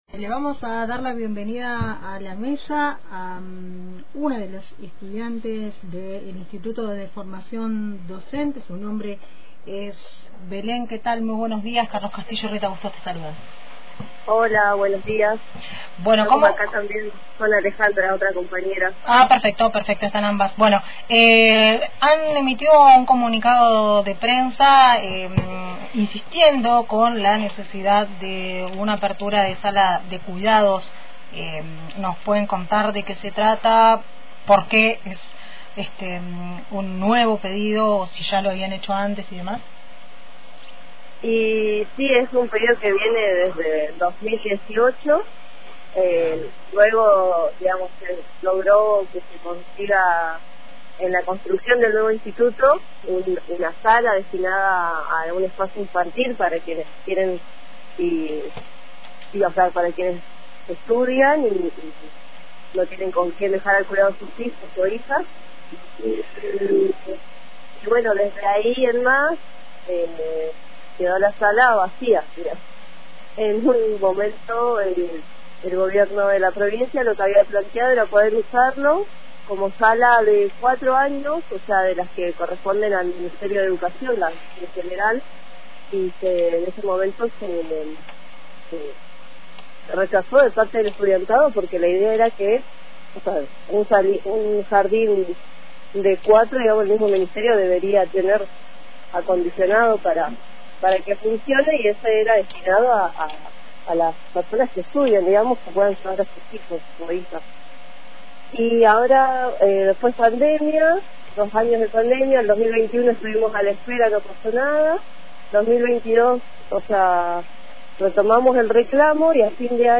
Exigen la apertura de cargos auxiliares para cubrir las nuevas tareas de una sala de cuidados y juegos dentro del IFDC. Escuchá la entrevista completa